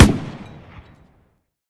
tank-mg-shot-9.ogg